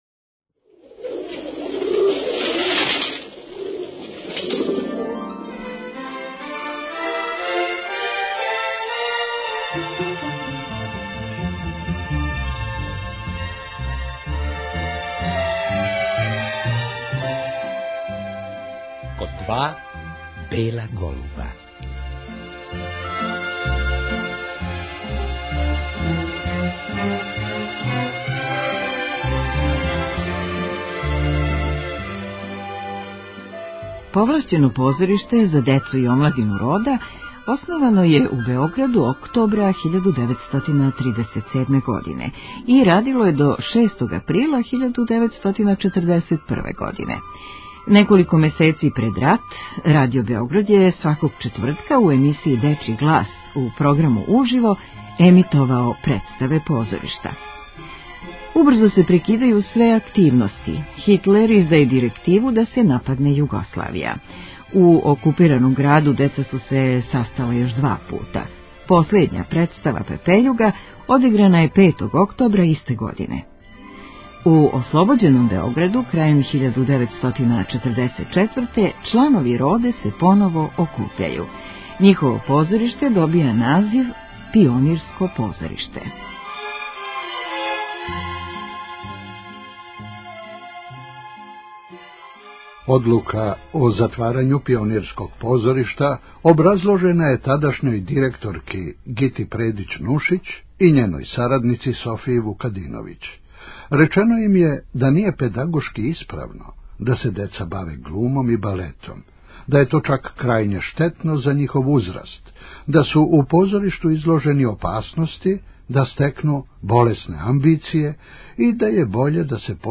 О томе нам је говорила 1984. године и ми ћемо поново чути тај снимак.